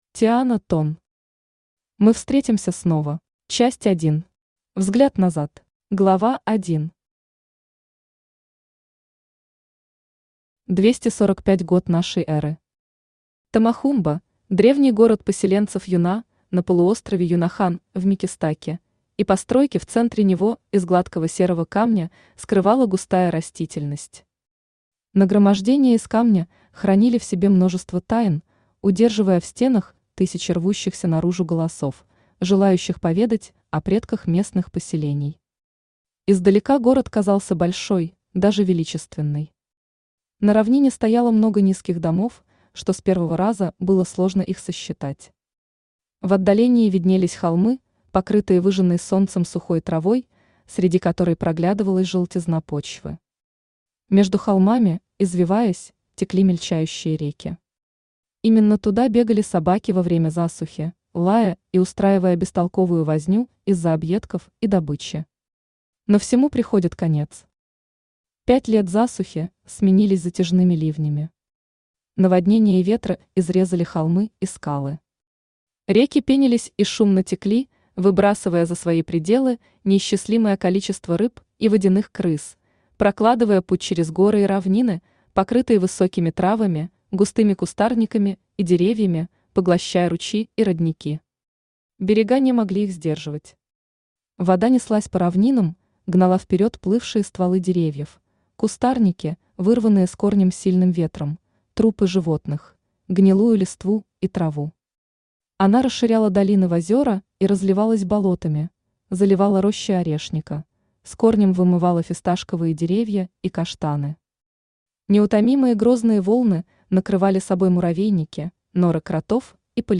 Aудиокнига Мы встретимся снова Автор Тиана Тон Читает аудиокнигу Авточтец ЛитРес.